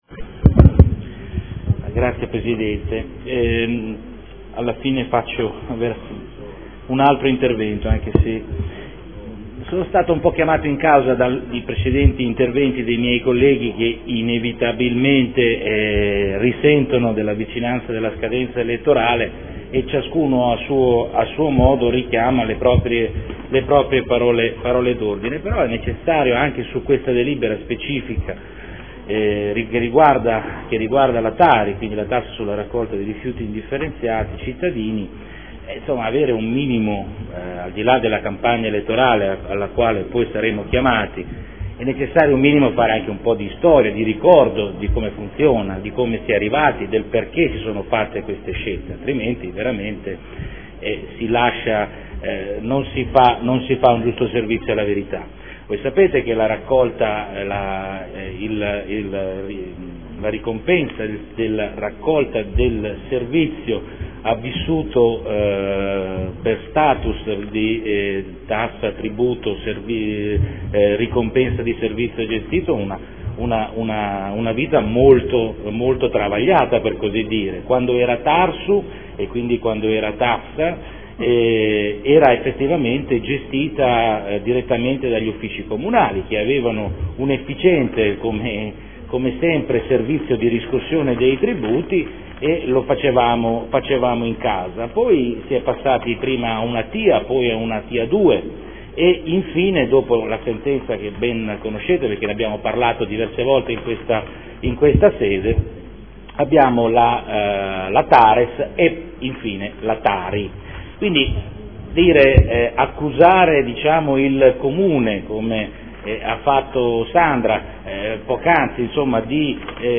Salvatore Cotrino — Sito Audio Consiglio Comunale
Seduta del 28/04/2014. Convenzione per l’affidamento della gestione della riscossione del Tributo comunale sui Rifiuti (TARI) e per la regolamentazione della fatturazione e dei pagamenti del Servizio di gestione dei rifiuti urbani ed assimilati (SGRUA)